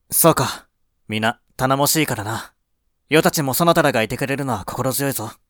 性別：男